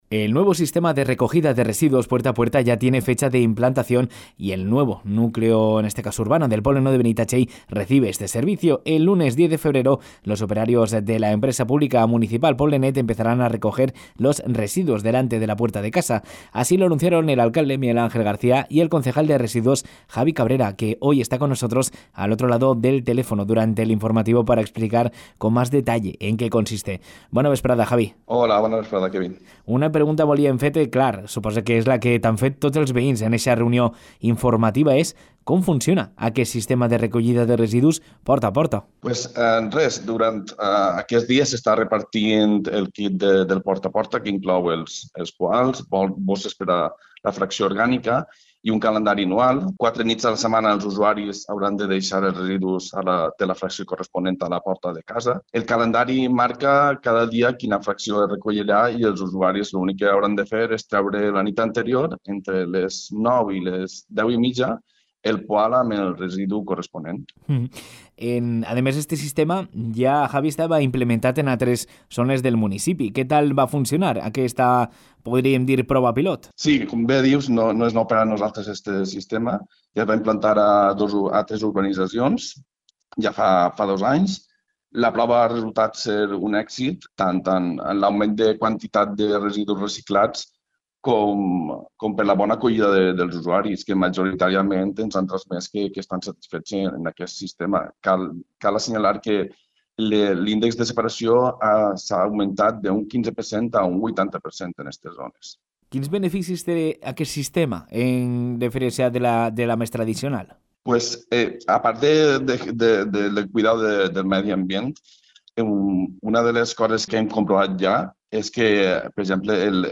Entrevista-Javi-Cabrera-Residus-Porta-a-porta.mp3